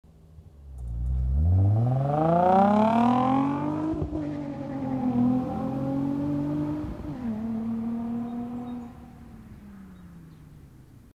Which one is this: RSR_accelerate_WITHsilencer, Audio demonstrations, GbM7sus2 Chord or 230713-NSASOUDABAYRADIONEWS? RSR_accelerate_WITHsilencer